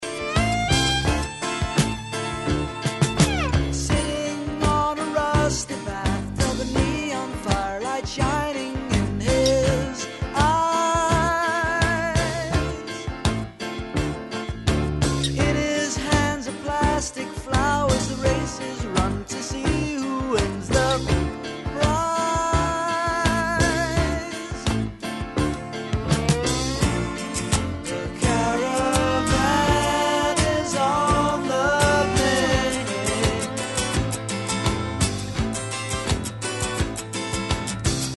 at Trident Studios, London